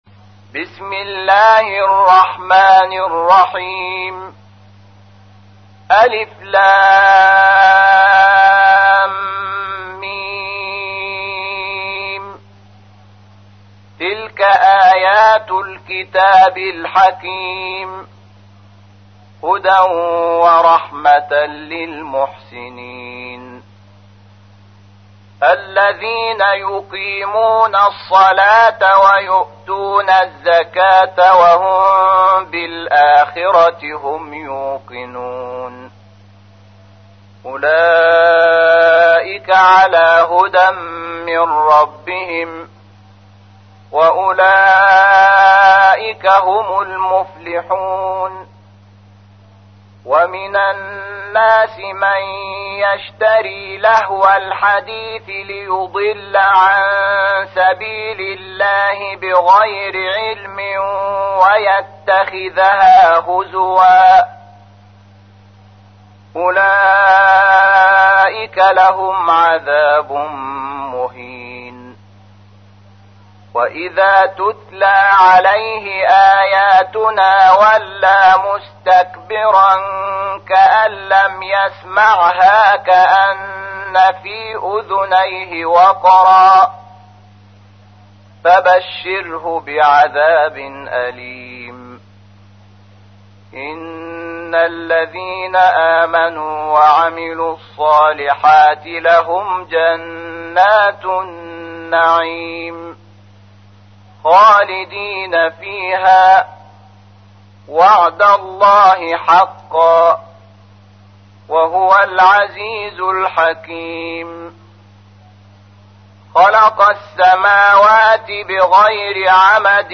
تحميل : 31. سورة لقمان / القارئ شحات محمد انور / القرآن الكريم / موقع يا حسين